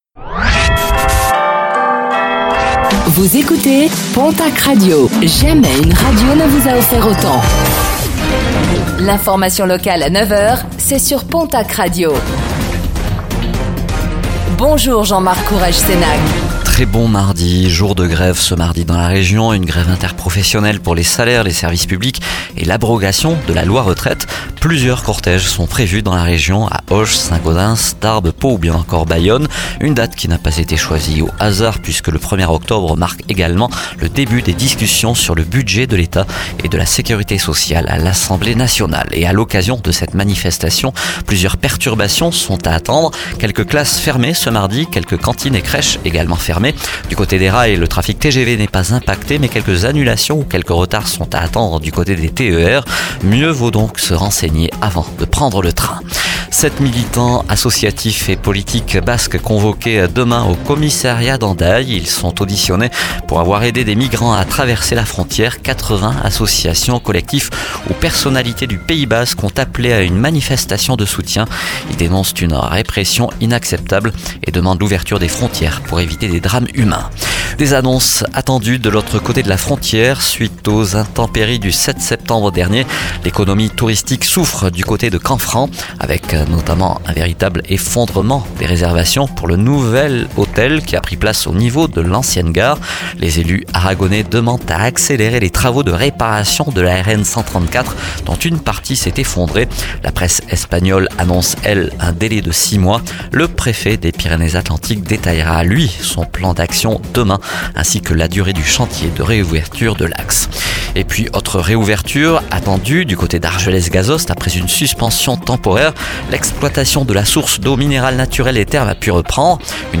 Infos | Mardi 1er octobre 2024